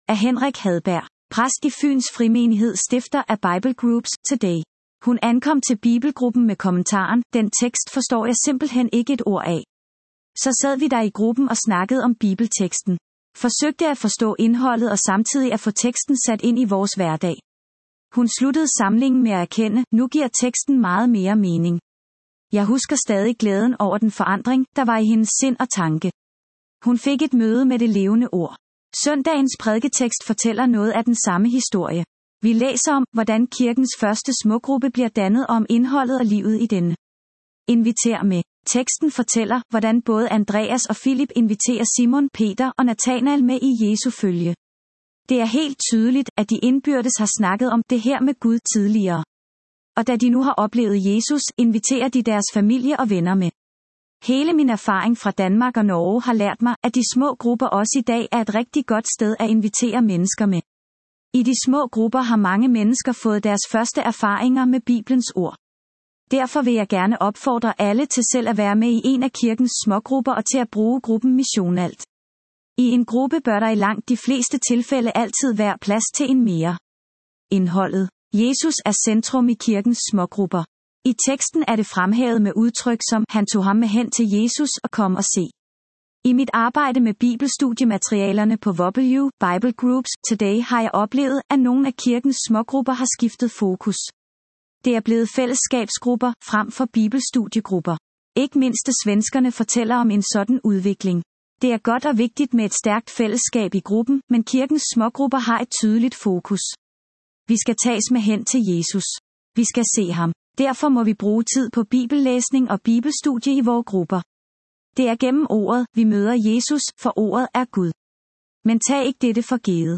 Ugens Prædiken